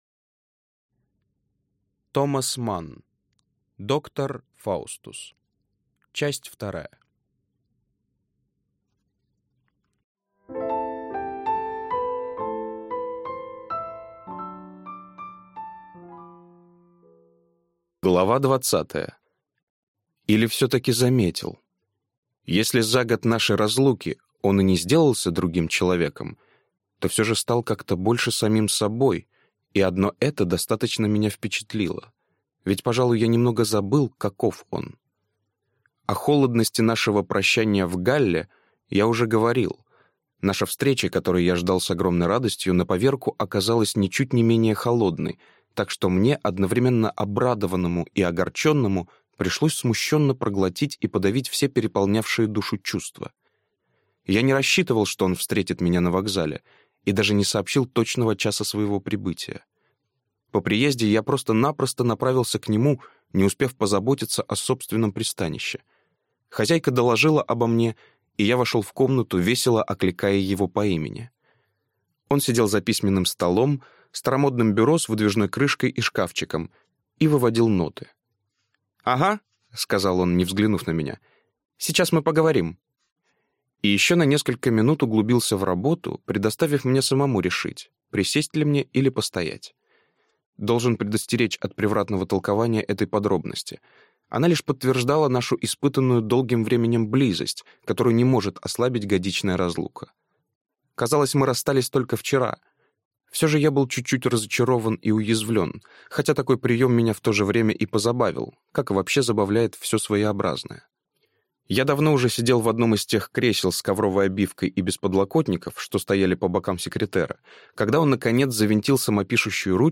Аудиокнига Доктор Фаустус. Часть 2 | Библиотека аудиокниг